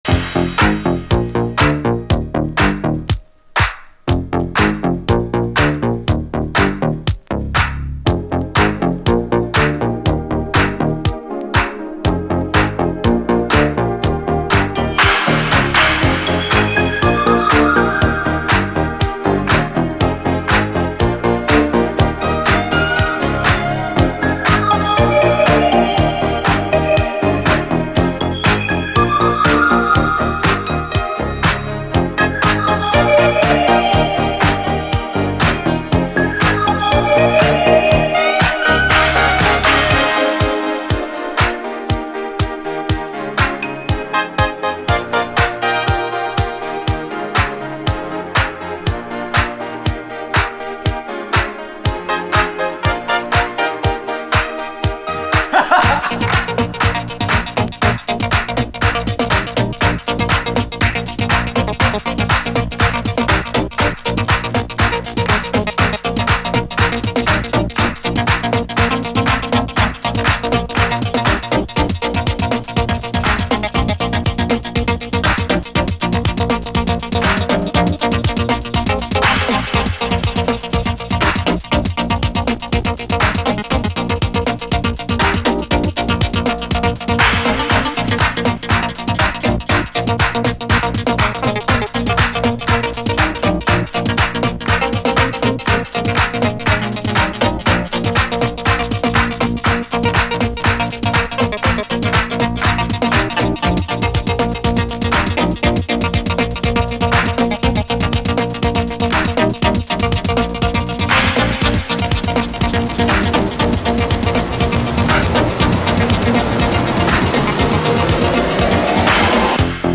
DISCO, 7INCH
ビキビキとダビーに展開するB面